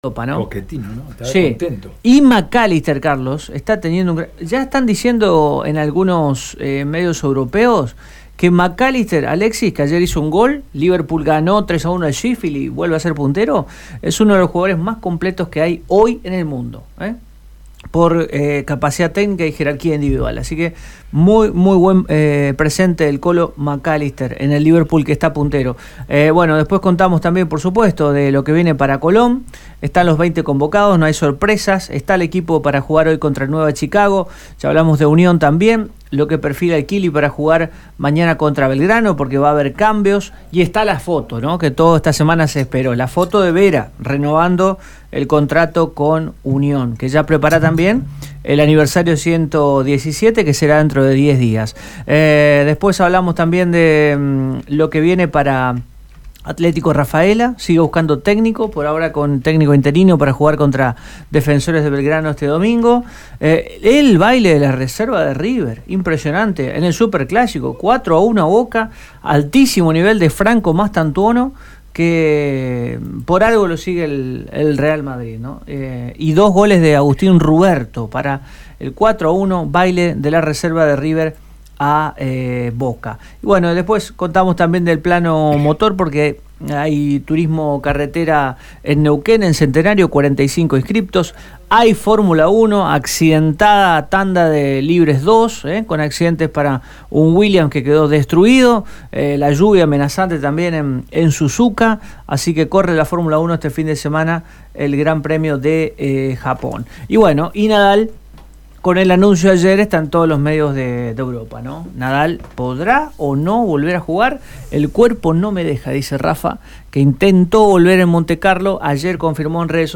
Una concentración de trabajadores que se hicieron presentes hoy en la sede de CLIBA, ubicada en Avenida Peñaloza al 7.800, donde exigieron la reincorporación de los despedidos.